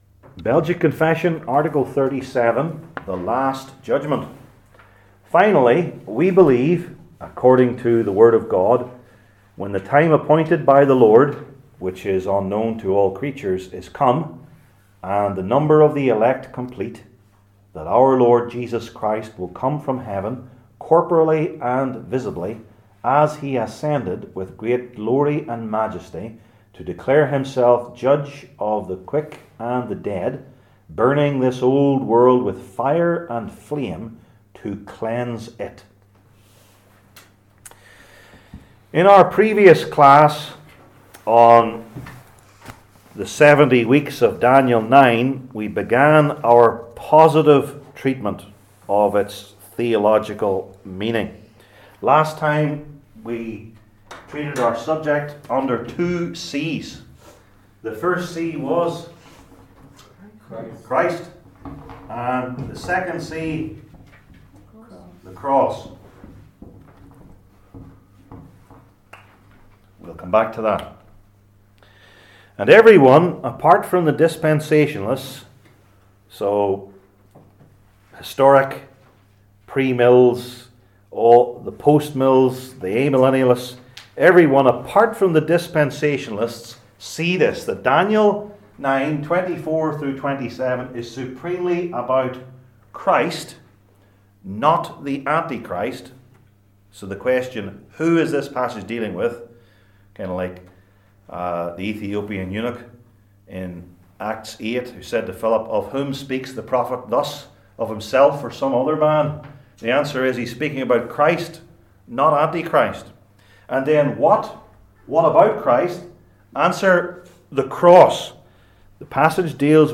Passage: Daniel 9:20-27 Service Type: Belgic Confession Classes